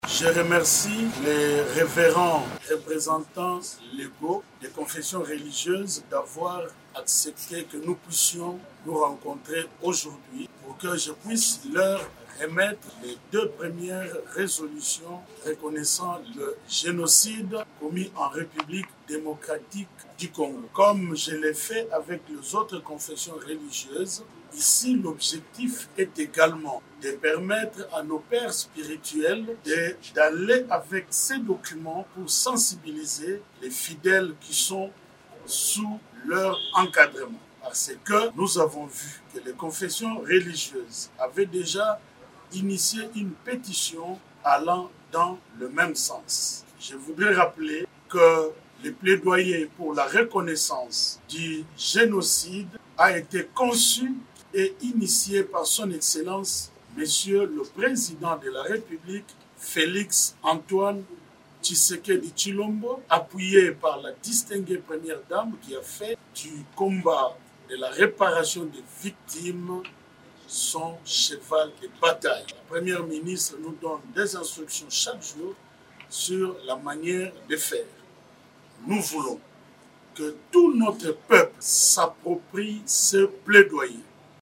Ces princes des églises ont donné leur accord, vendredi 28 novembre, lors d’un entretien avec le ministre des Droits humains, à Kinshasa.
Pour sa part, le ministre des Droits humains , Samuel Mbemba se réjouit que ces interlocuteurs aient accepté de s’engager dans ce combat, initié par le Chef de l’Etat, Félix Tshisekedi